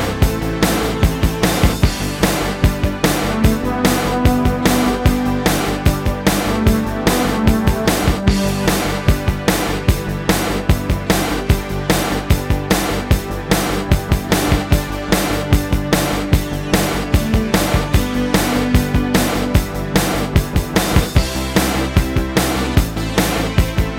Four Semitones Down And No Guitar Rock 3:29 Buy £1.50